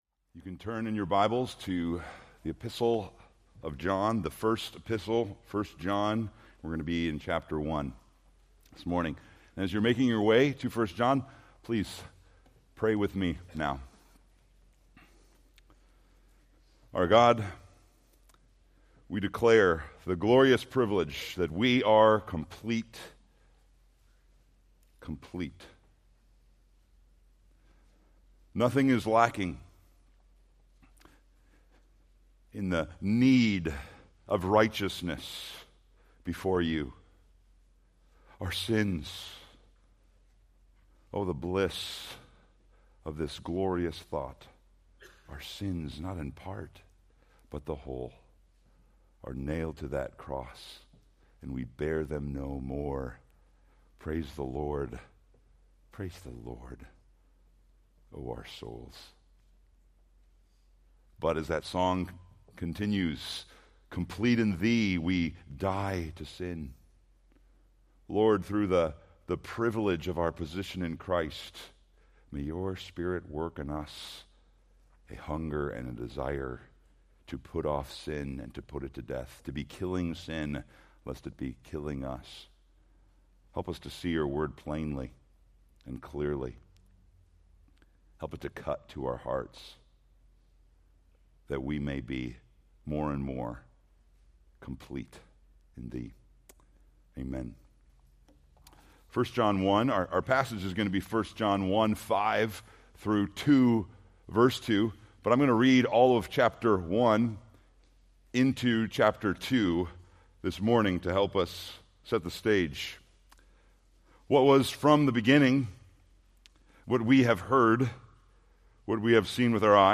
Sermon Details